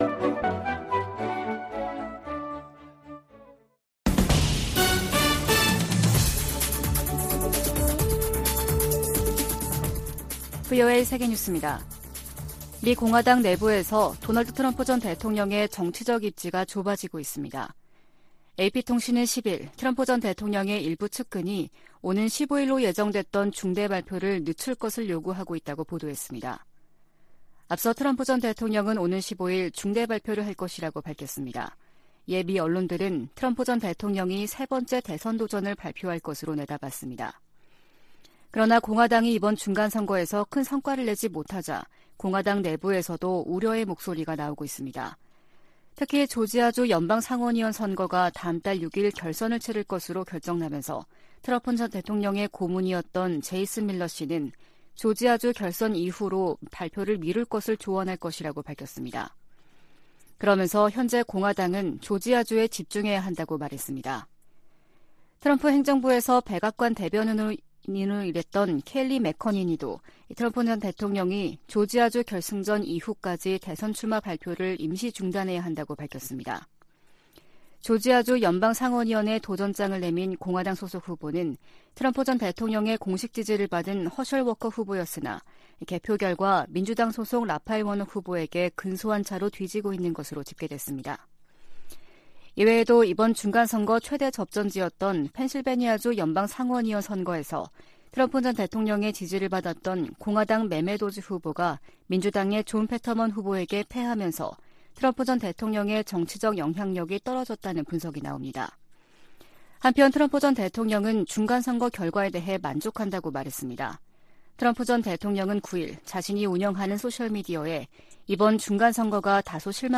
VOA 한국어 아침 뉴스 프로그램 '워싱턴 뉴스 광장' 2022년 11월 11일 방송입니다. 미국 중간선거 결과 의회 다수당의 변화가 예상되는 가운데 한반도 등 대외 현안들에 어떤 영향을 미칠지 주목됩니다. 미국과 한국, 일본, 중국의 정상들이 G20 정상회의에 참석하면서 북한 문제를 둘러싼 다자 외교전이 펼쳐질 전망입니다. 북한이 핵물질 생산을 위해 영변 핵시설을 지속적으로 가동하고 있다고 전 국제원자력기구(IAEA) 사무차장이 밝혔습니다.